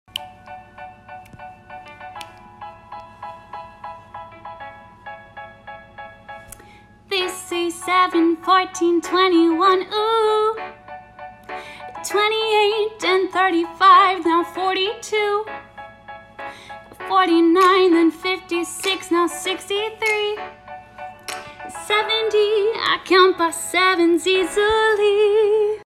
Hopefully this re-mix will help you!